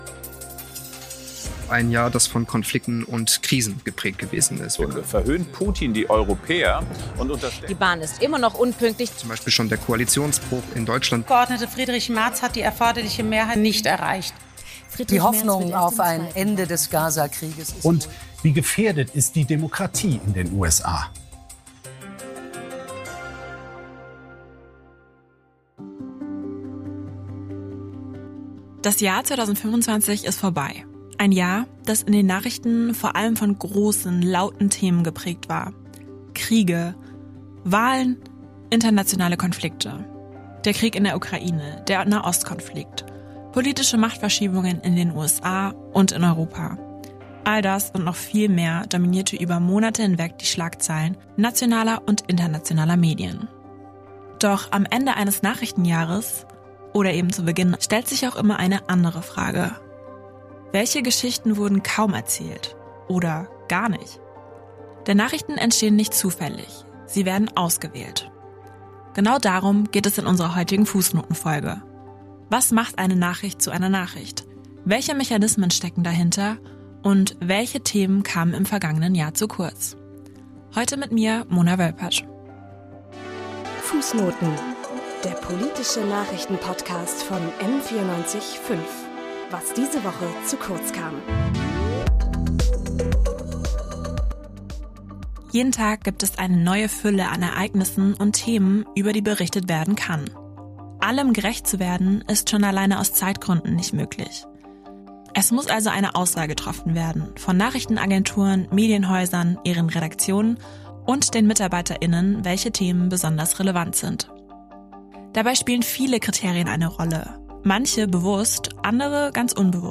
Dafür führen wir ein Gespräch mit unseren Redakteur:innen, die jeweils ein Thema mitbringen, das ihnen ein Anliegen ist, sowie mit Wissenschaftlerinnen und Politikerinnen. Gemeinsam werfen wir so einen genaueren Blick auf zwei aktuelle Beispiele, die medial zu kurz gekommen sind: den anhaltenden Krieg im Sudan und den Grenzkonflikt an der polnischen Grenze.